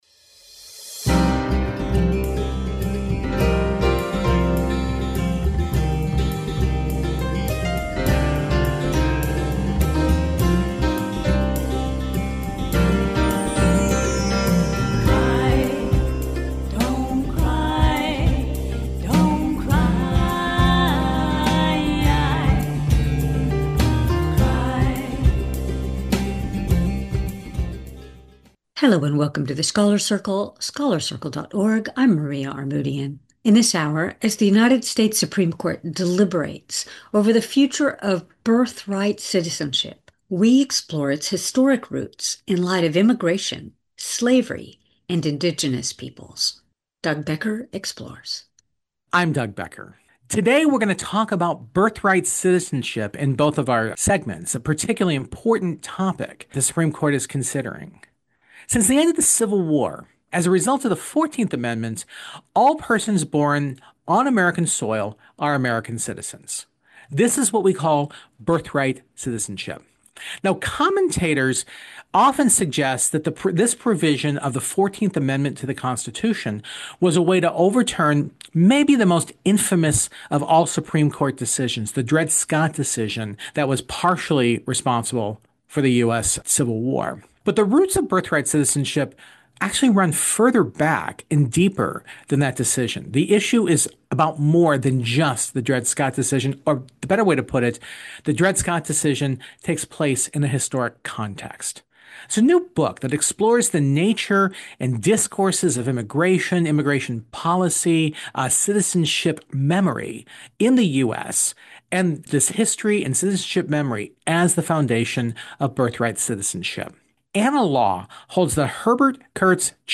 Scholars’ Circle – Birthright Citizenship, its Historic Roots in Immigration, Slavery, & Indigenous Peoples – April 26, 2026 | The Scholars' Circle Interviews